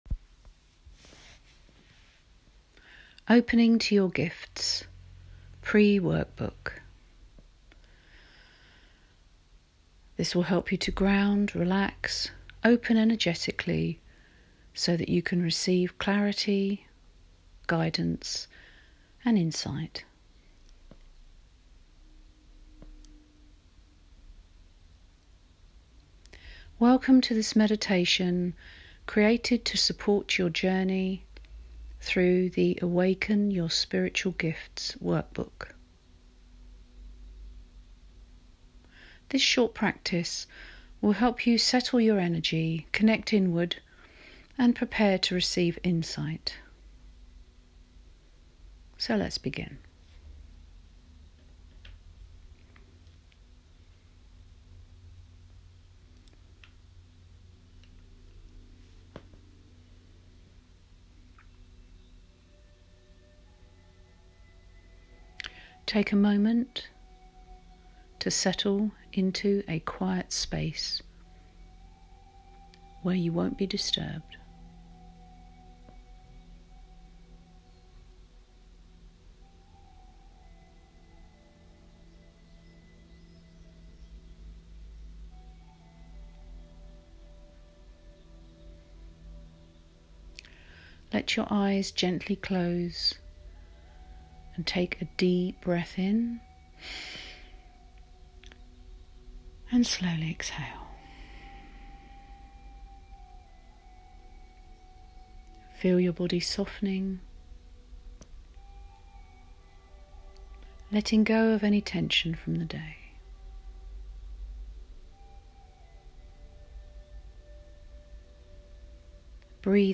Meditation one